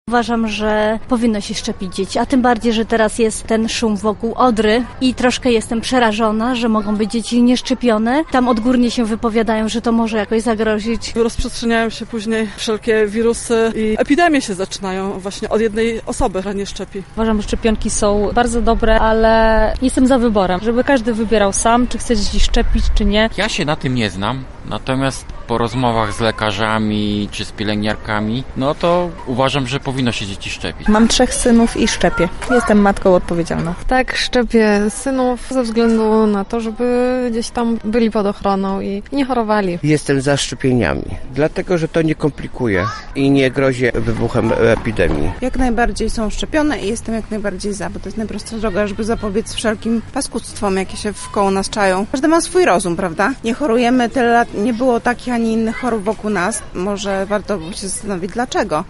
Zapytaliśmy mieszkańców Lublina o to czy wiedzą jak zapobiegać chorobie, jakie ma objawy, a także czy korzystają ze szczepień.
sonda-odra.mp3